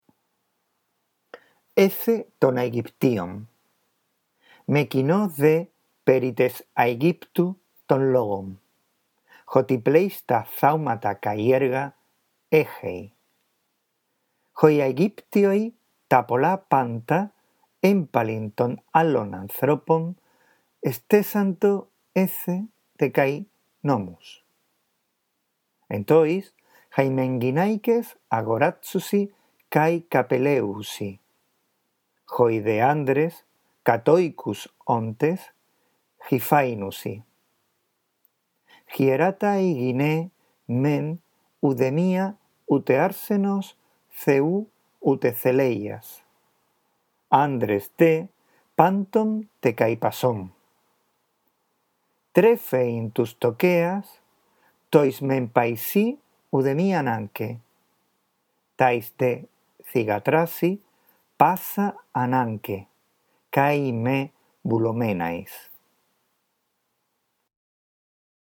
La audición de este archivo te guiará en la lectura del texto griego